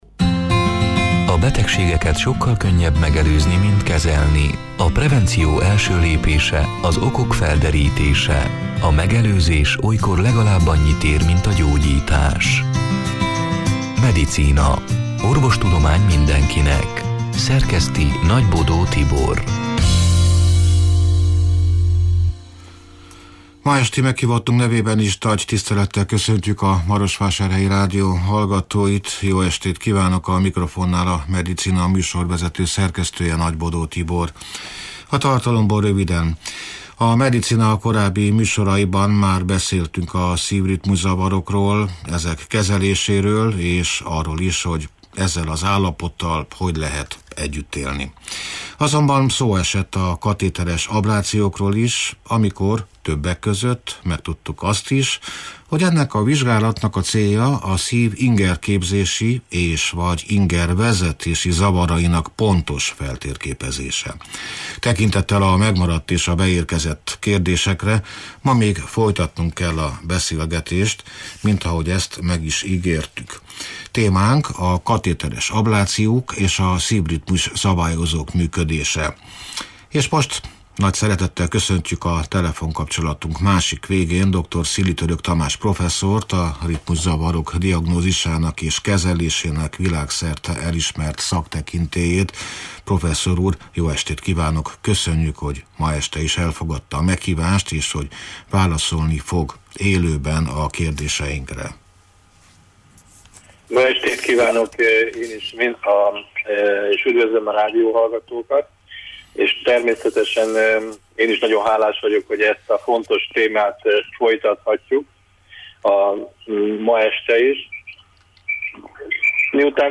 (elhangzott: 2024. november hatodikán, este nyolc órától élőben)